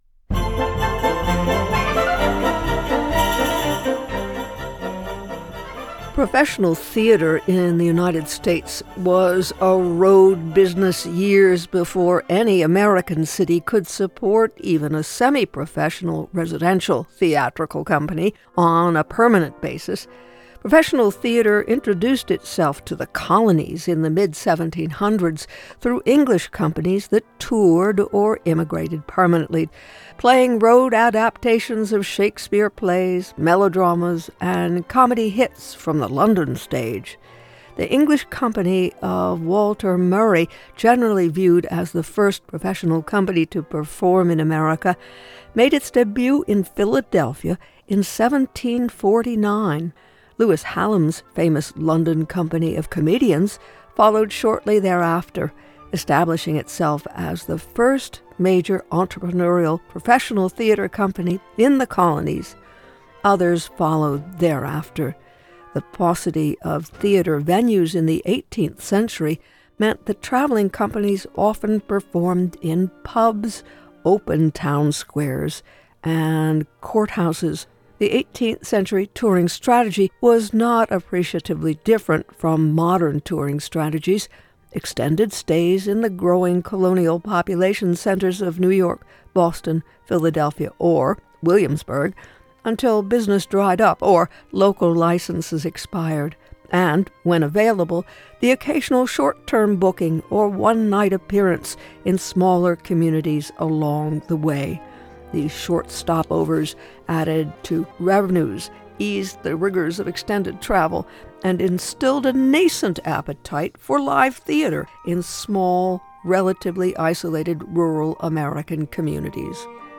Join her weekdays at Noon for interviews, reviews and commentaries on films, books, jazz, and classical music.